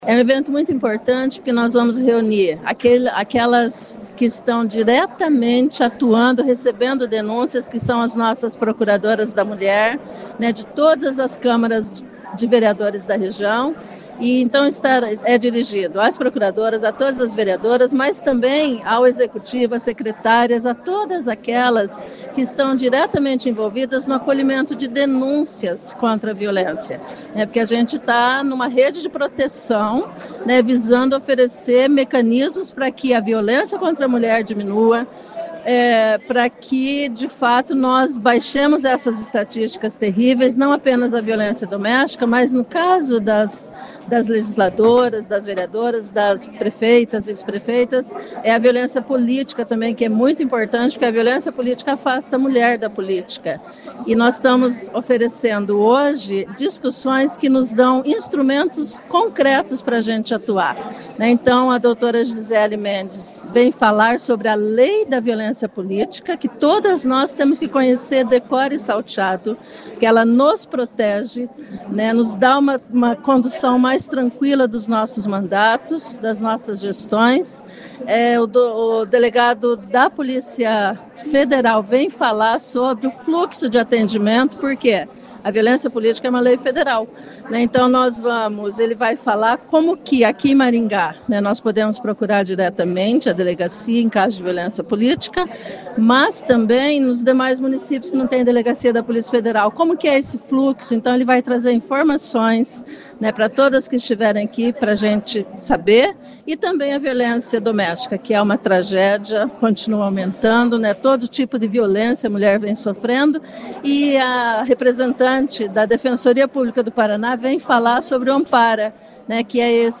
Ouça o que diz a Procuradora da Mulher de Maringá, Professora Ana Lúcia, sobre o evento: